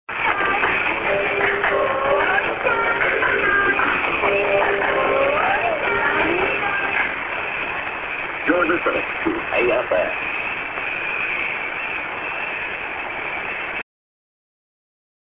ID"your lisning AFN"